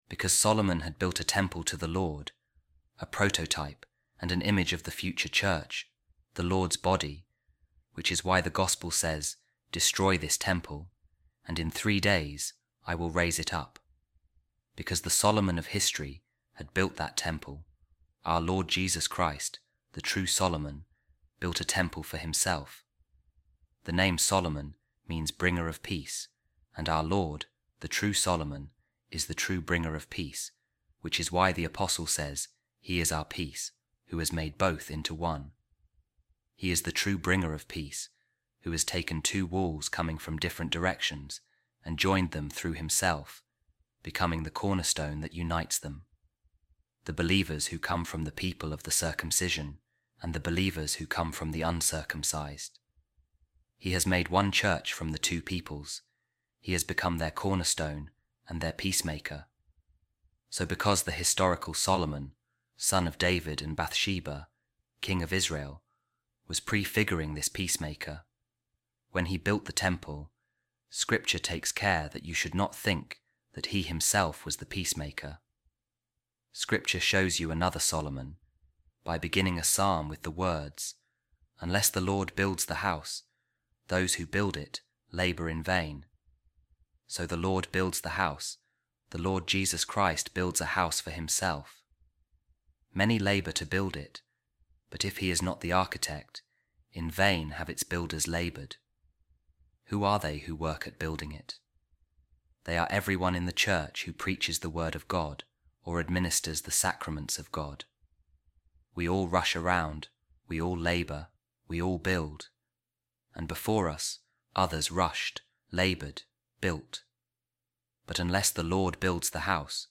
A Reading From The Discourses Of Saint Augustine On The Psalms | The Lord Jesus Christ Is The True Solomon